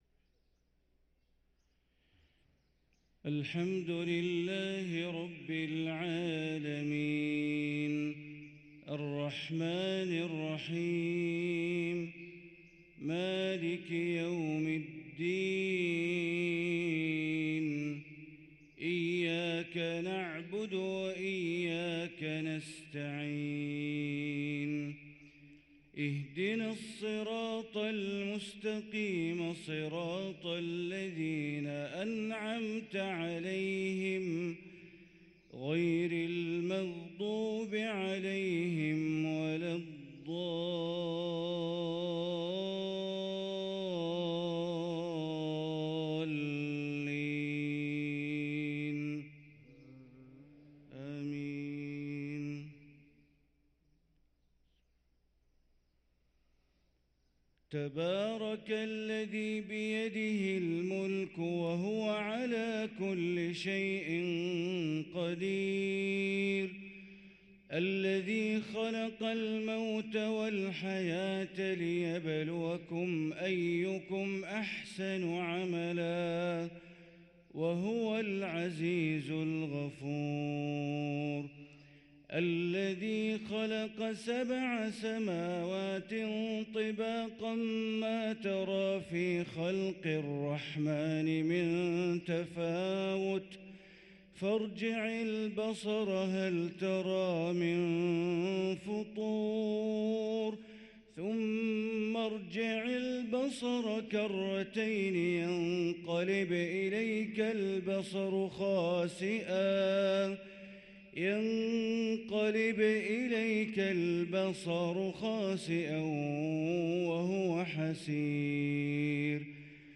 صلاة الفجر للقارئ بندر بليلة 29 جمادي الآخر 1444 هـ